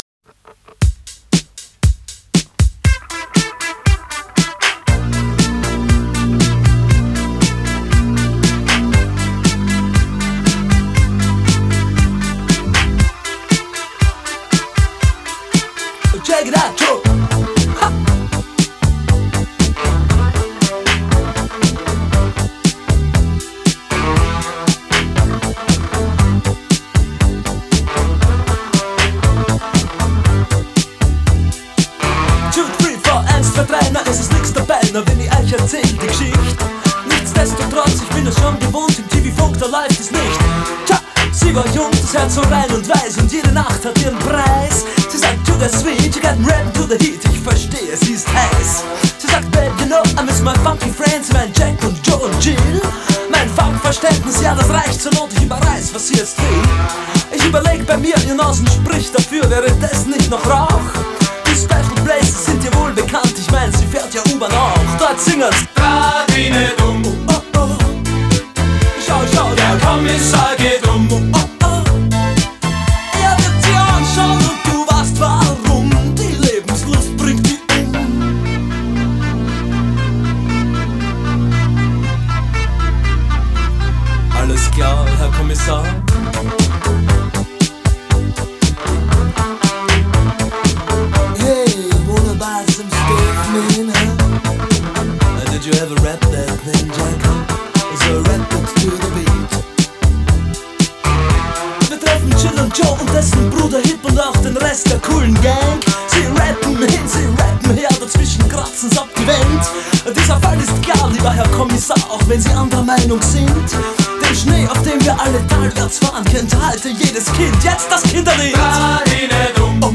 Genre: Rock,New Wave